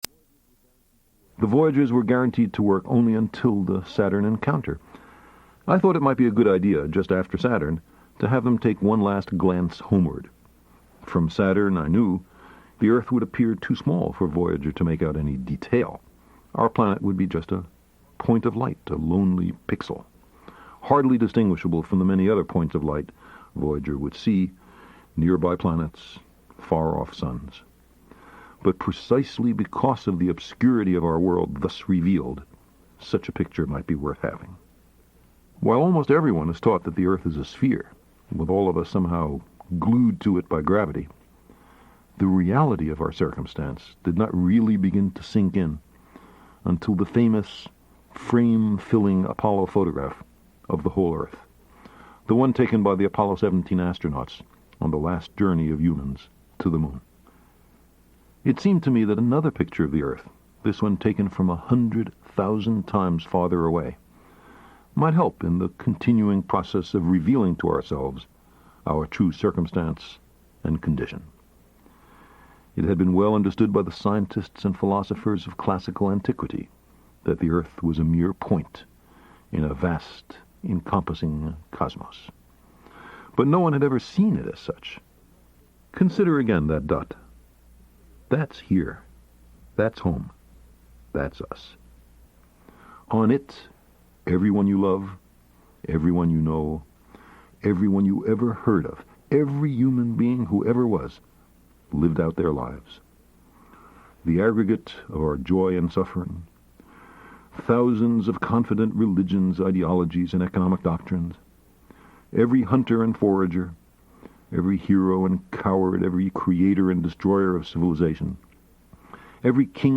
The Pale Blue Dot (Full Speech by Carl Sagan) [pQobd6o3ujU].opus